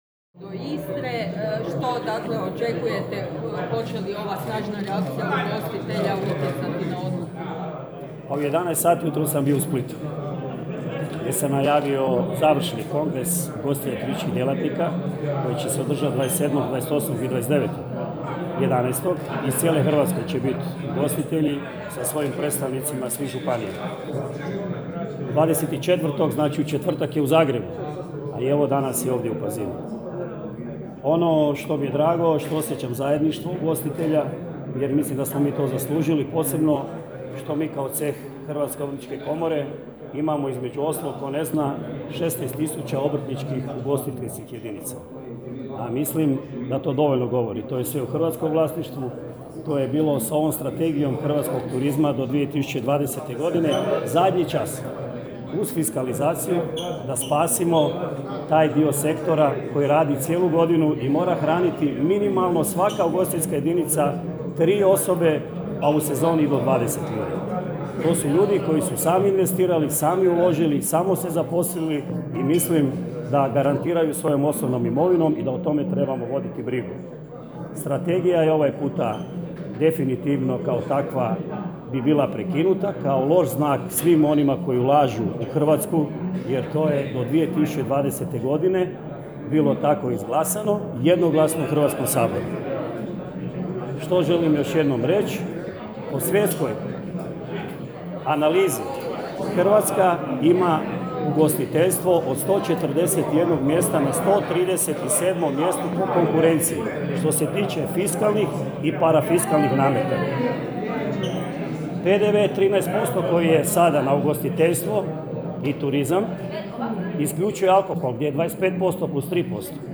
U ponedjeljak, 21.11.2016. u pazinskom Spomen domu okupilo se više stotina ugostitelja na skupu protiv povećanja PDV-a u ugostiteljstvu.